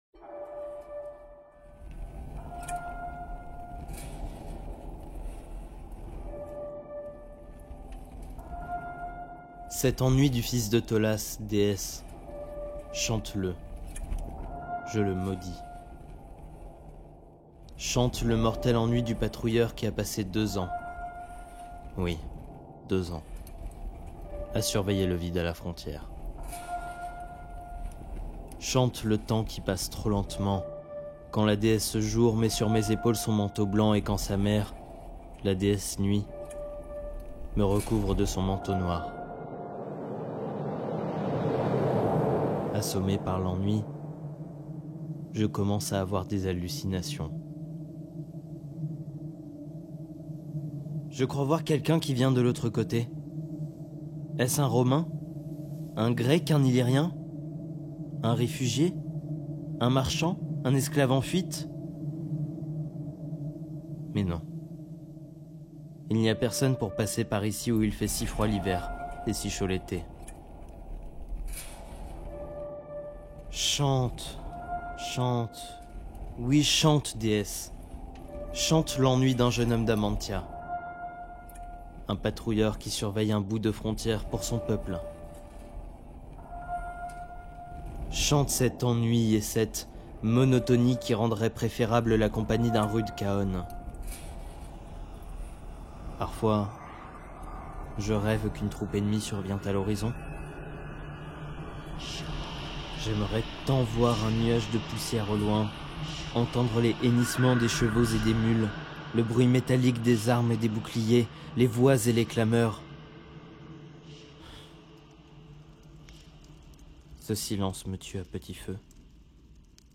Pour une meilleure écoute en son stéréo, nous vous invitons à utiliser un casque.